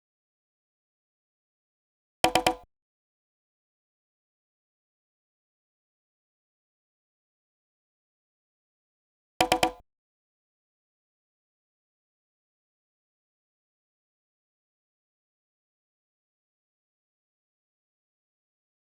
drill (!)_[PBS] Compressed Snare_3.wav